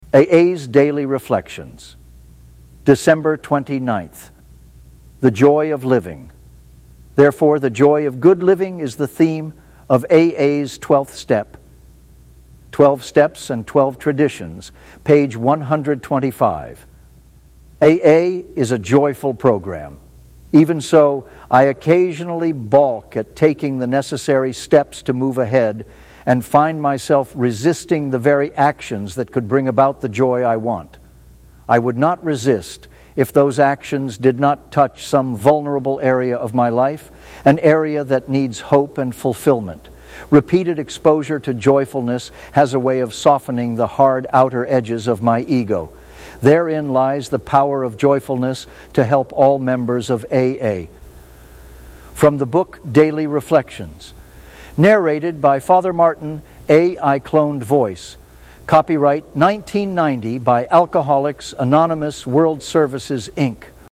A.I. Cloned Voice.